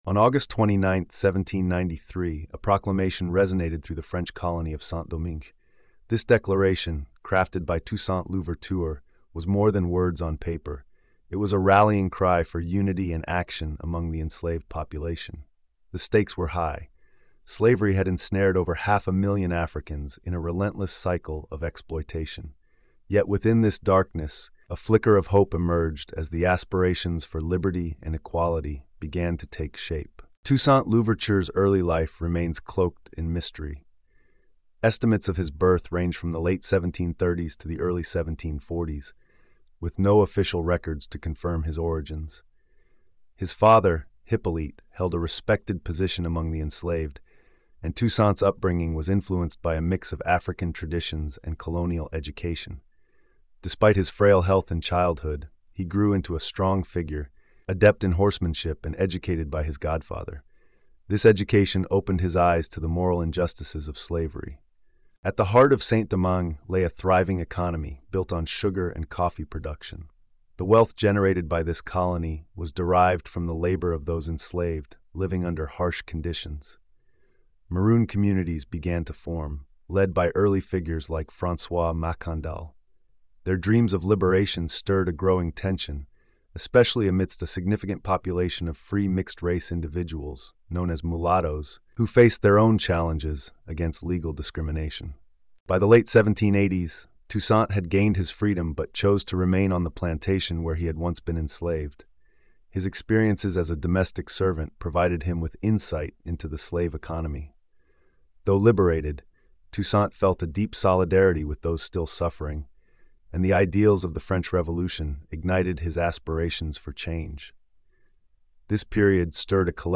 This narrative presents a comprehensive and dramatized exploration of the life and revolutionary rise of Toussaint Louverture, situating his personal journey within the broader political, social, and economic upheavals of late eighteenth-century Saint-Domingue.